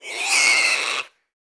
monster / wild_boar / dead_1.wav
dead_1.wav